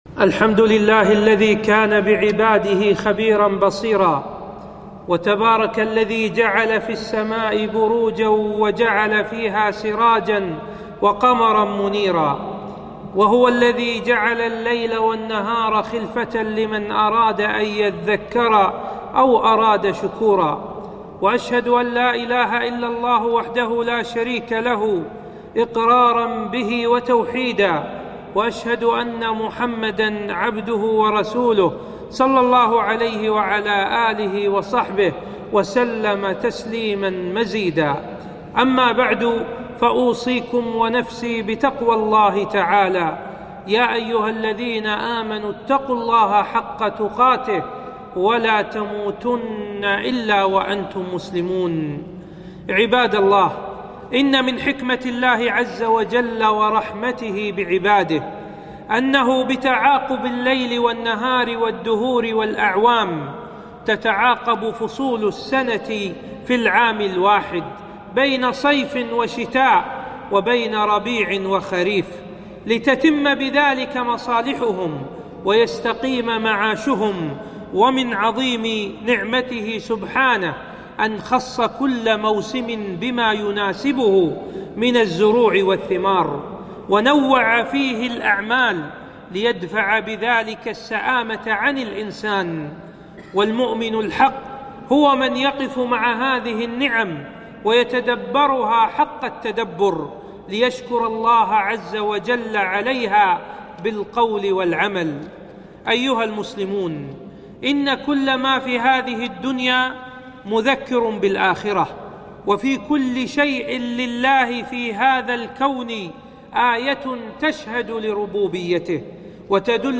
خطبة - آداب وعبر في فصل الشتاء